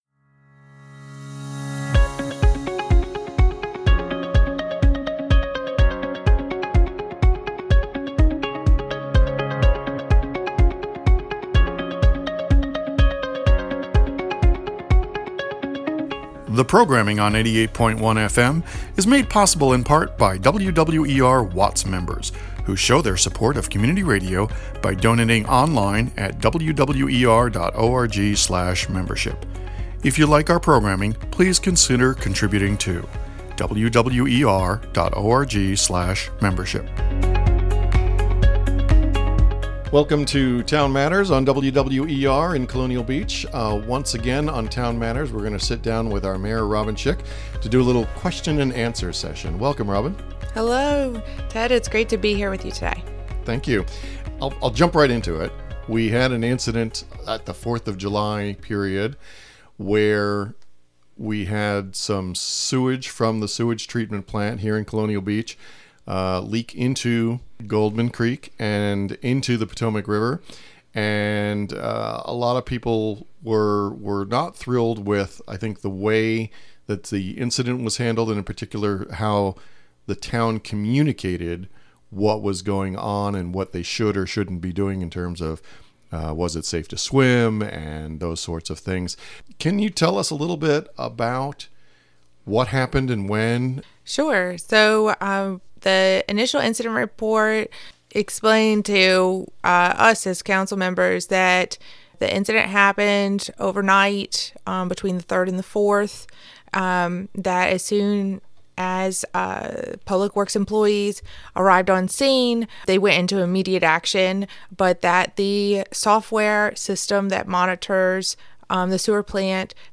Q&A with Mayor - September 2023 - WWER 88.1 FM
In this month’s Q&A with Mayor Robin Schick, we spent some time reviewing the recent sewage spill and why official information from the town fell short of what it should have been. We also continued to answer specific questions from listeners on a variety of topics.